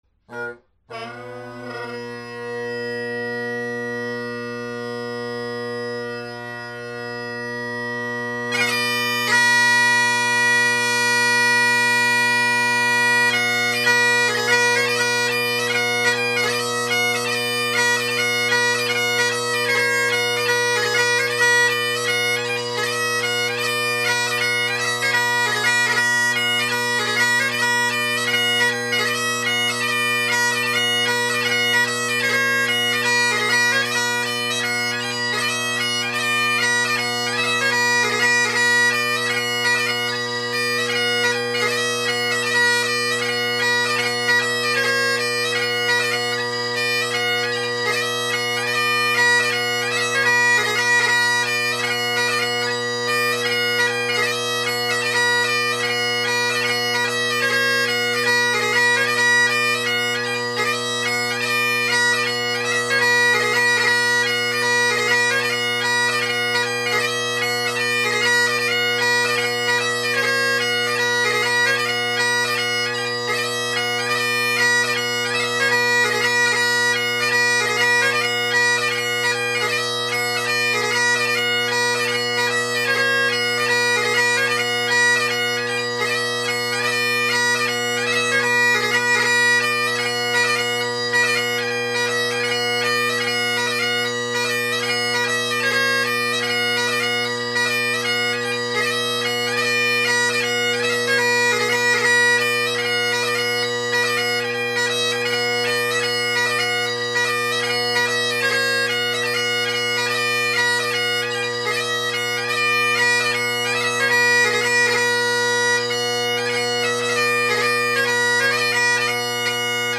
Drone Sounds of the GHB, Great Highland Bagpipe Solo
The recordings below were artificially amplified as I forgot to reset the gain on my Zoom H2 after setting it to a quieter setting yesterday.
Still a little tenor quiet, but I believe this lends just a little more ease when tuning the drones along with some added stability.